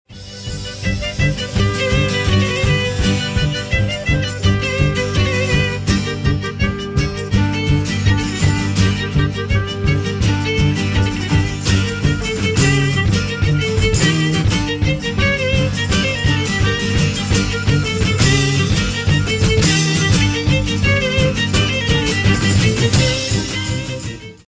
More Klezmer.